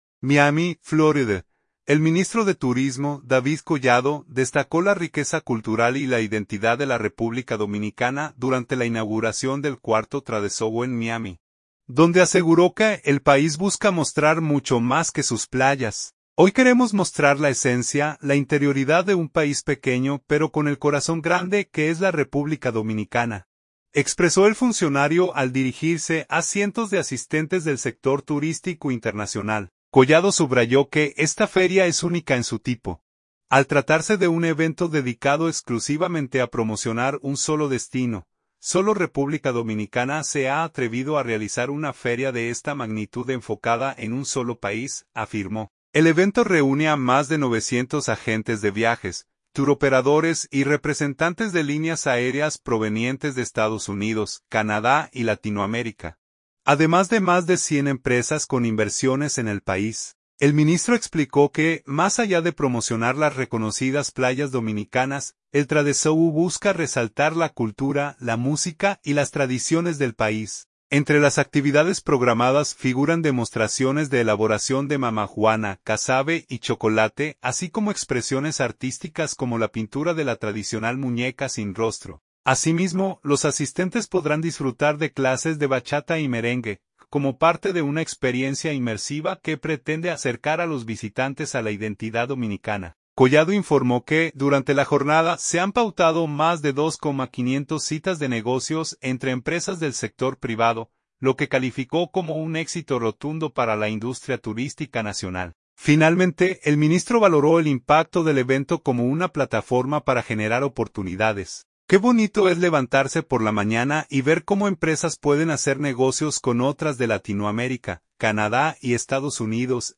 Miami, Florida. – El ministro de Turismo, David Collado, destacó la riqueza cultural y la identidad de la República Dominicana durante la inauguración del cuarto Tradeshow en Miami, donde aseguró que el país busca mostrar mucho más que sus playas.
“Hoy queremos mostrar la esencia, la interioridad de un país pequeño, pero con el corazón grande, que es la República Dominicana”, expresó el funcionario al dirigirse a cientos de asistentes del sector turístico internacional.